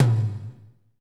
TOM A C M08L.wav